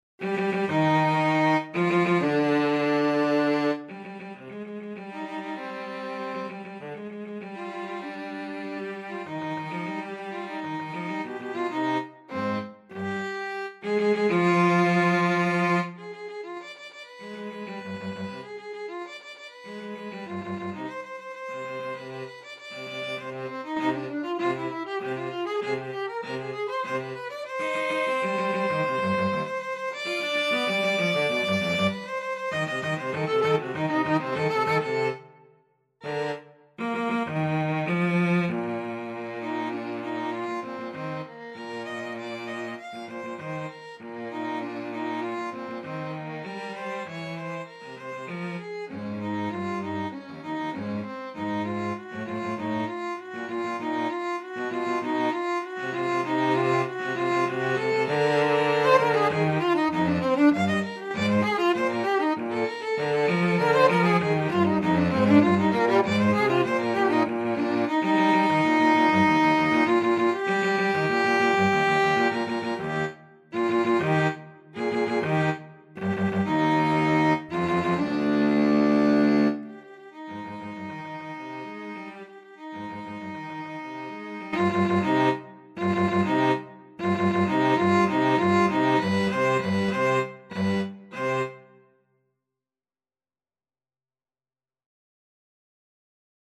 2/4 (View more 2/4 Music)
Allegro con brio (=108) =98 (View more music marked Allegro)
Classical (View more Classical Violin-Cello Duet Music)